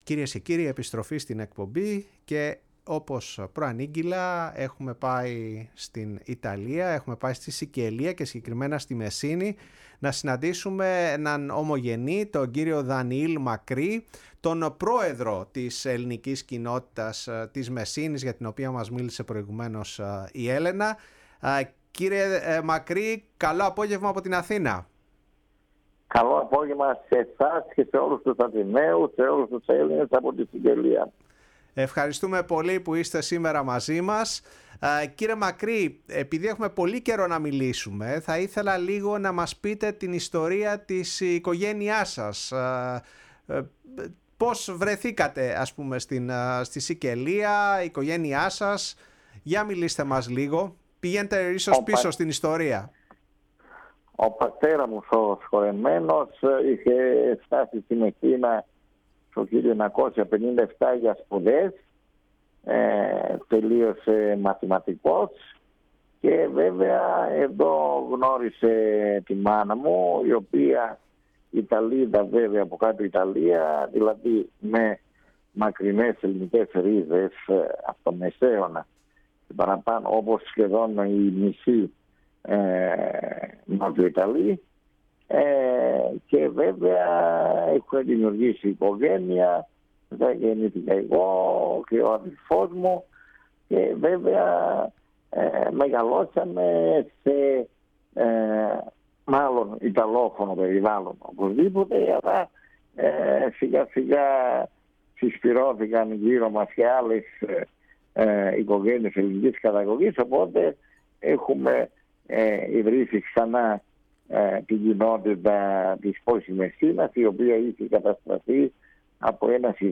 μιλώντας στο ραδιόφωνο της Φωνής της Ελλάδας και στην εκπομπή “Η Παγκόσμια Φωνή μας”